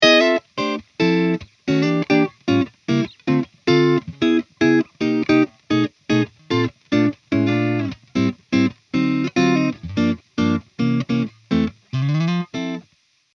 Jazz chord melody